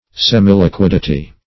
Search Result for " semiliquidity" : The Collaborative International Dictionary of English v.0.48: Semiliquidity \Sem`i*li*quid"i*ty\, n. The quality or state of being semiliquid; partial liquidity.